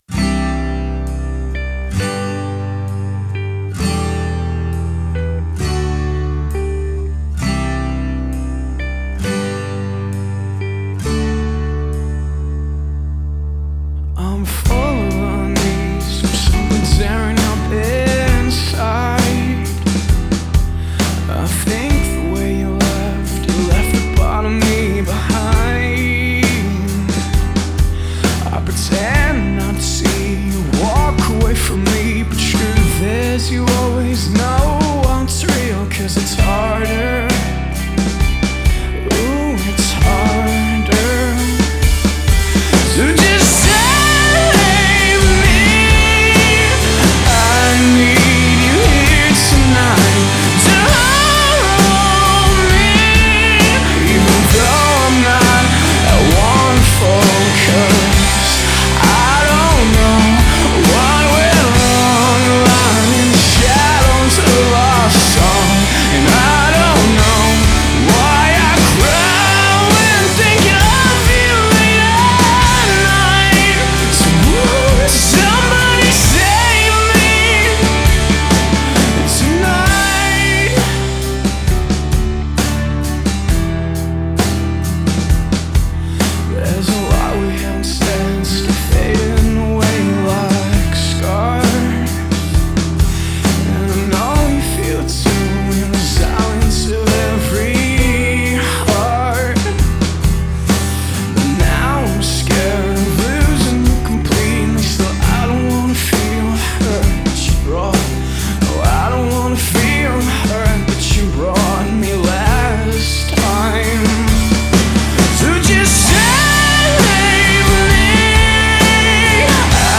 hab noch nicht mal die hälfte der spuren gebraucht.